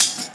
Track 02 - Percussion OS 02.wav